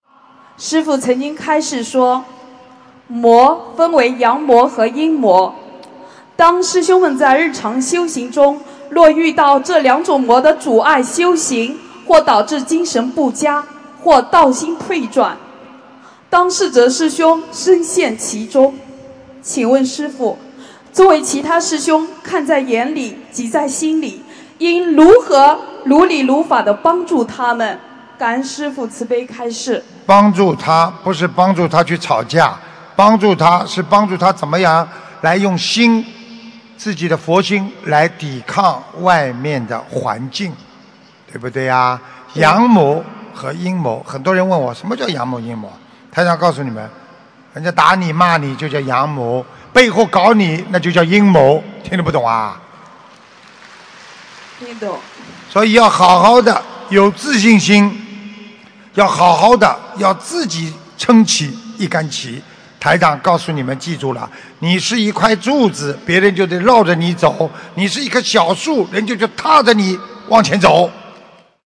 如何帮助修行中遇到魔障的同修┃弟子提问 师父回答 - 2017 - 心如菩提 - Powered by Discuz!